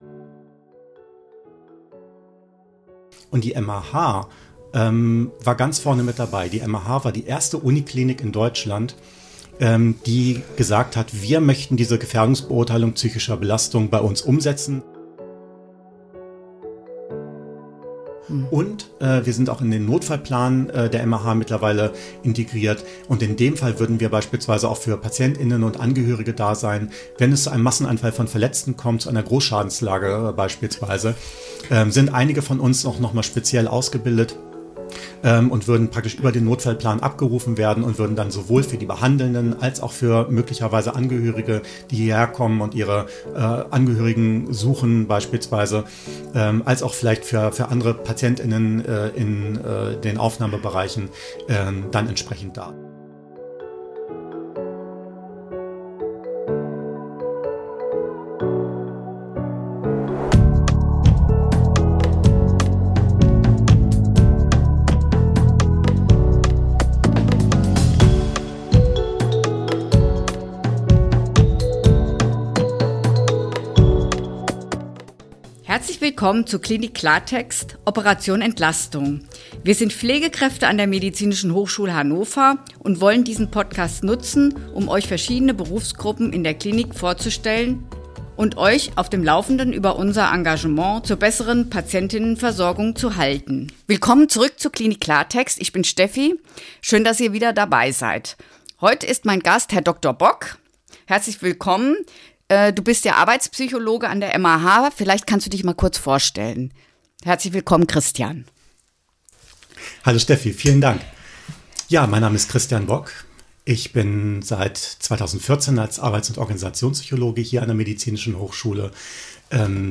Arbeits- und Organisationspsychologie & Krisenbegleitung – Ein Interview